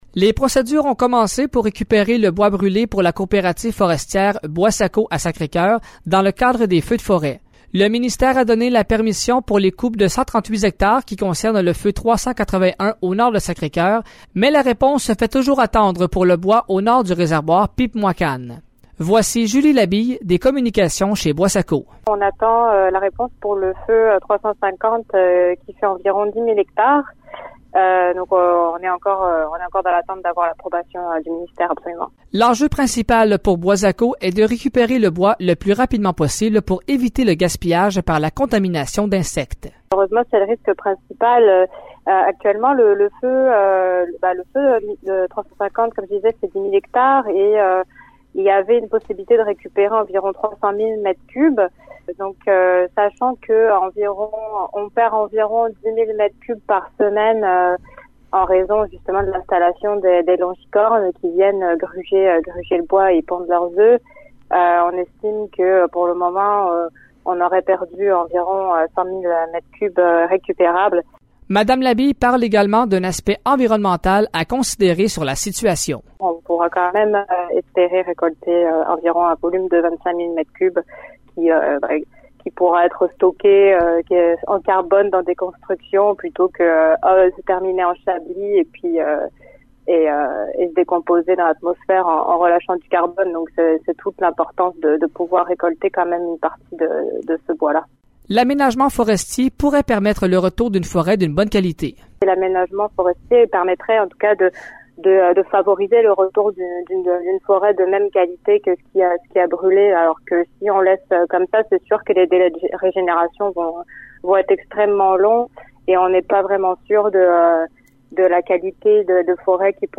Voici le reportage